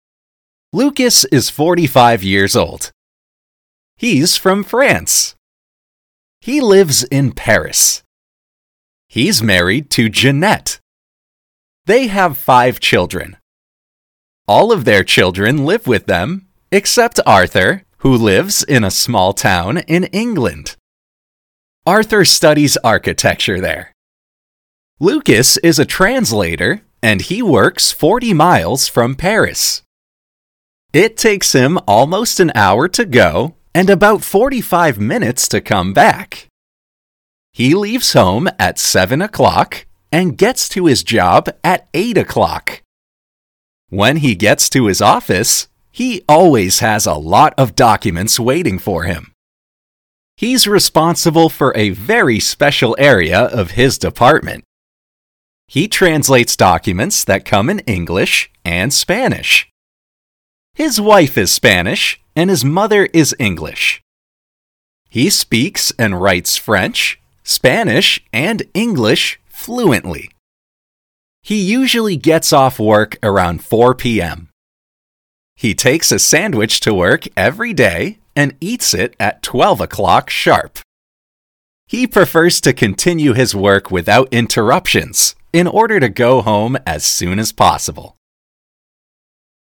Shadowing